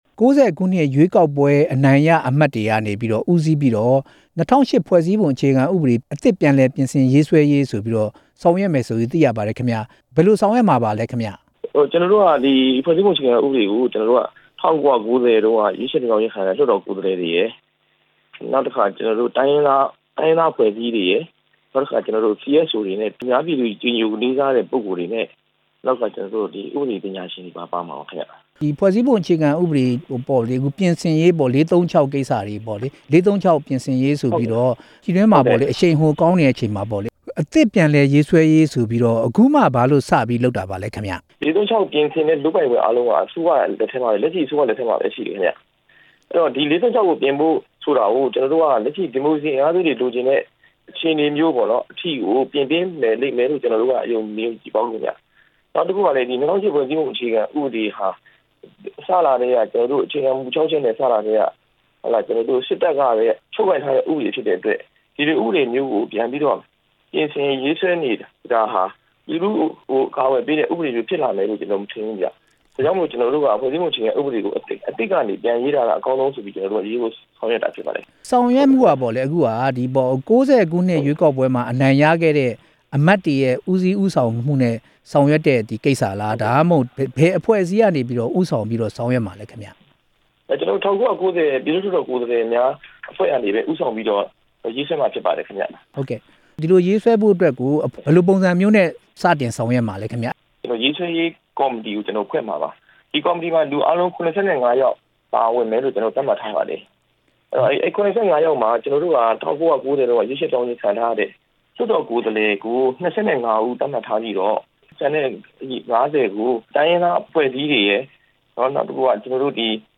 ဖွဲ့စည်းအုပ်ချုပ်ပုံအခြေခံဥပဒေအသစ် ရေးဆွဲရေး လှုပ်ရှားမှှုနဲ့ ပတ်သက်ပြီး မေးမြန်းချက်